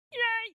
funnyYay.mp3